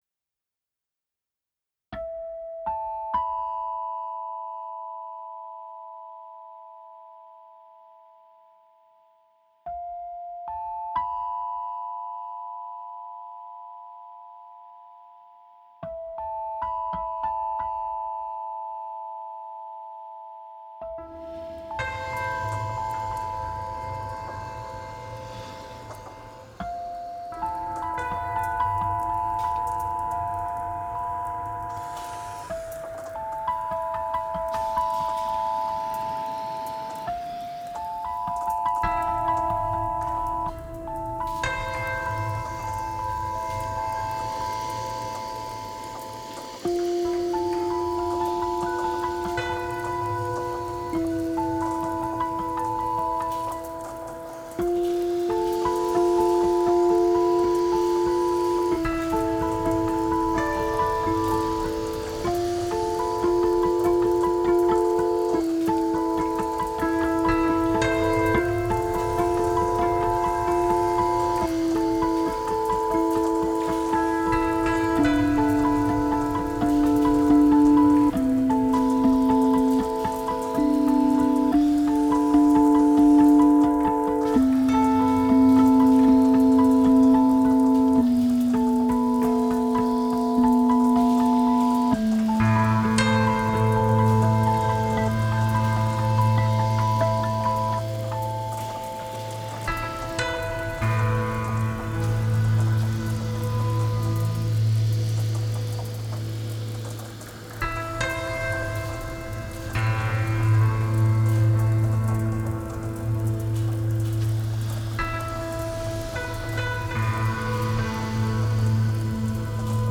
十几年间，不接演出，不宣传，不出面，躲在昭通唱着自己的摇滚。